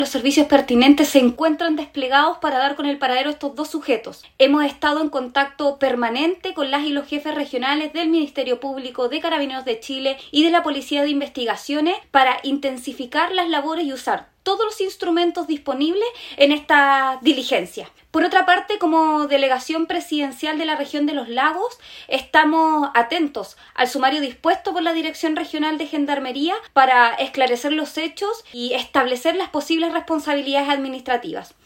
Al respecto de esta situación, la delegada presidencial regional, Giovanna Moreira, dijo que existe coordinación entre todos los actores vinculados a la seguridad pública, para lograr la detención de los fugados y re ingresarlos al cumplimiento de sus respectivas condenas.